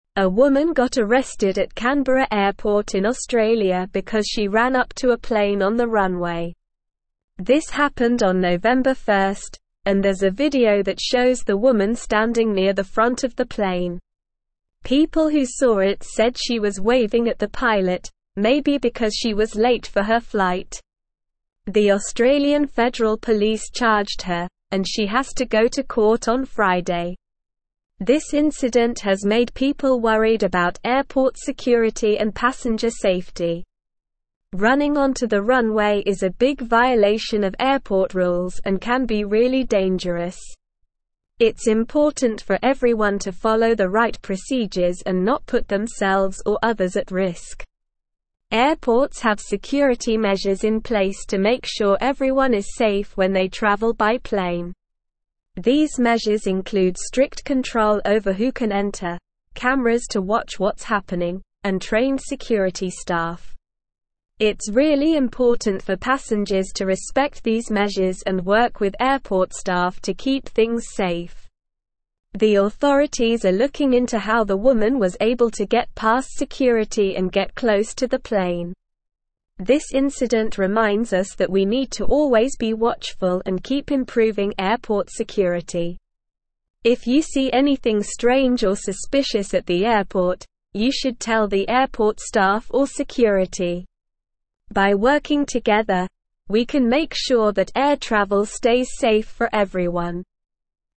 Slow
English-Newsroom-Upper-Intermediate-SLOW-Reading-Woman-Arrested-for-Running-onto-Tarmac-at-Canberra-Airport.mp3